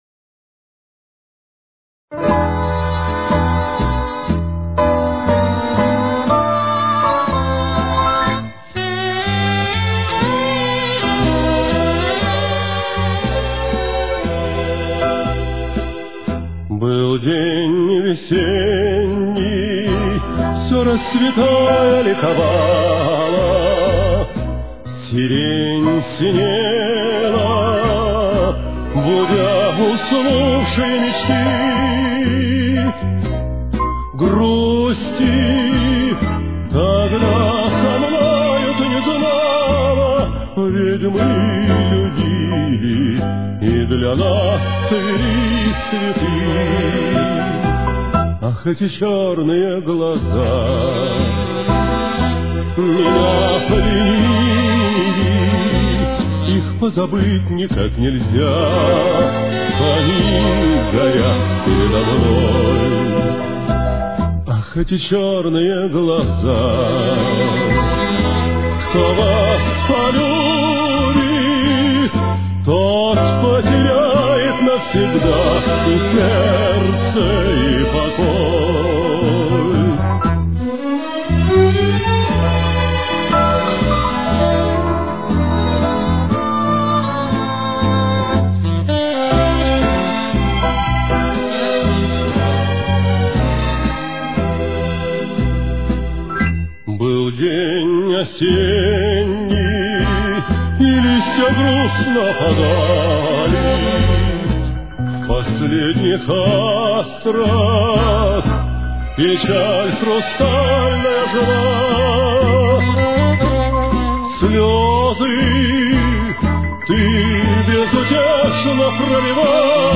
Темп: 123.